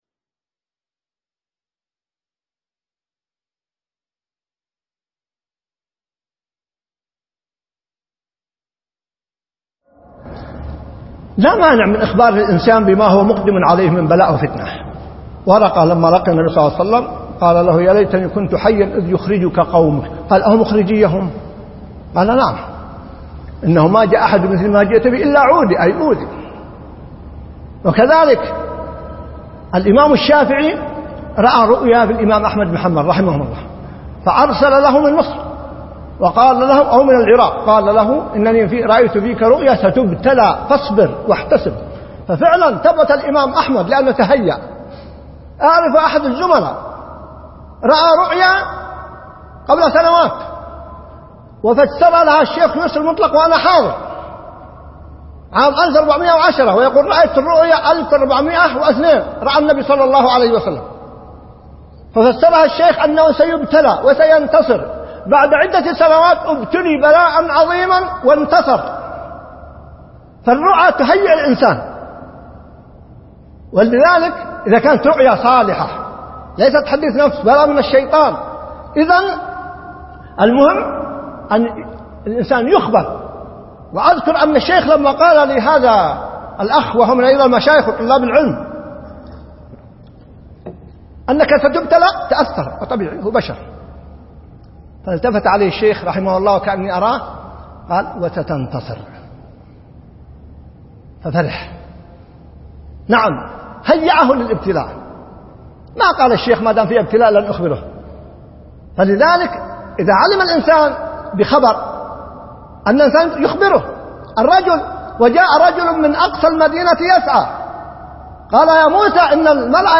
دروس فى علوم القرآن